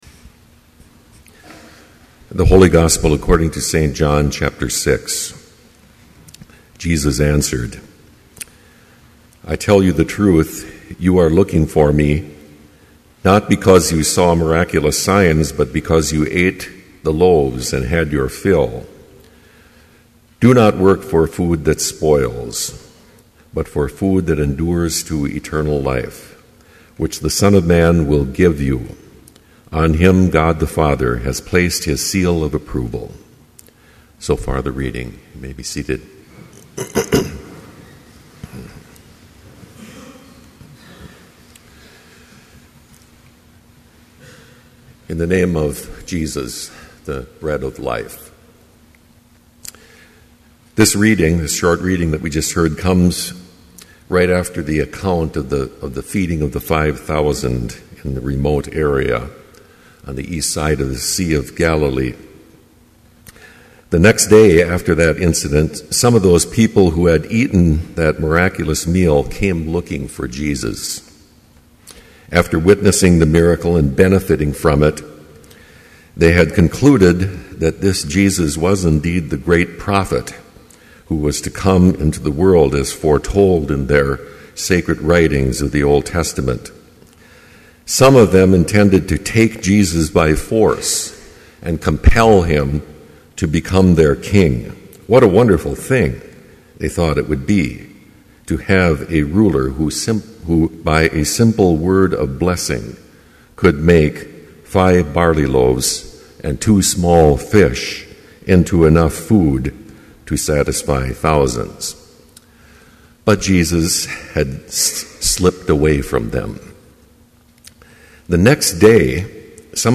Complete service audio for Chapel - March 21, 2012
Prelude Hymn 74, Awake, My Soul, and with the Sun Scripture Reading: John 6:26-27 Homily Prayer Hymn 266, O Bread of Life from Heaven Benediction Postlude